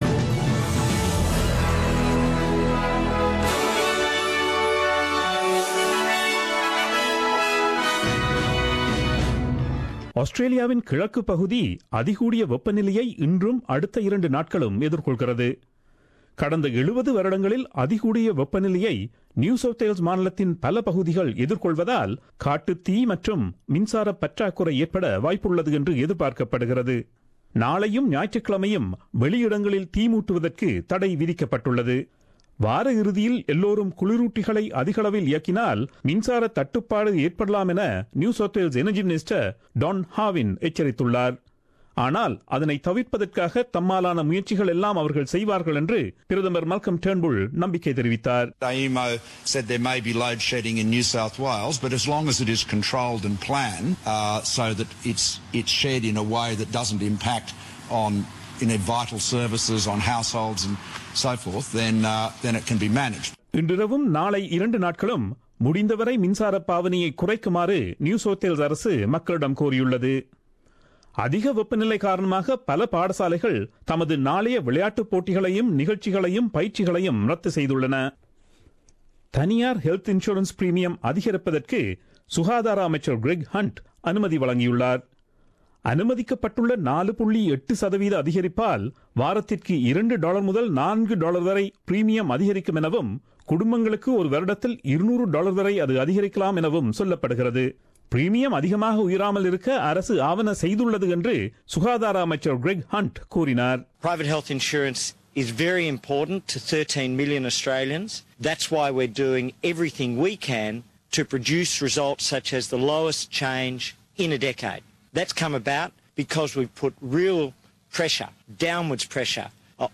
Australian news bulletin aired on Friday 10 Feb 2017 at 8pm.